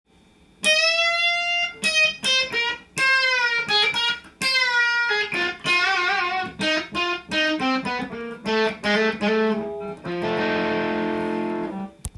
リアピックアップまたは、ブリッジに近くまで
チリチリした甲高い音になり、
かなり個性的で目立つ音になります。